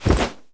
foley_jump_movement_throw_08.wav